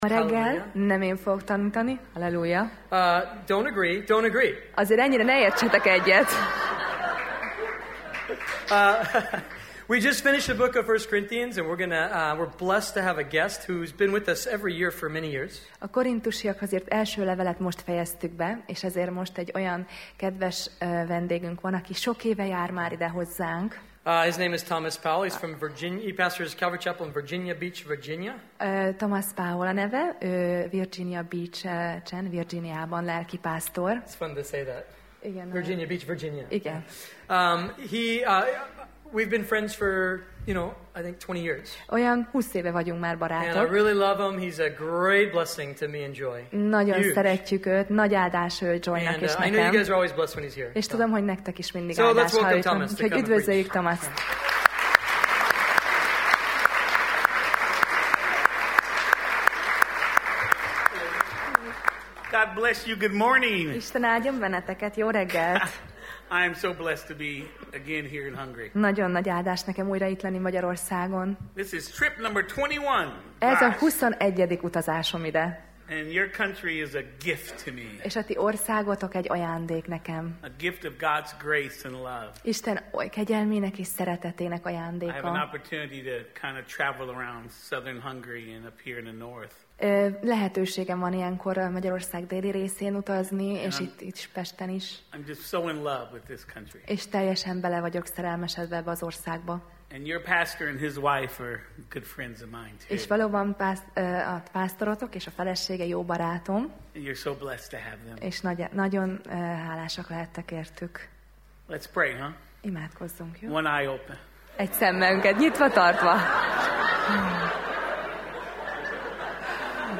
Sorozat: Tematikus tanítás Passage: Jeremiás (Jeremiah) 6:16 Alkalom: Vasárnap Reggel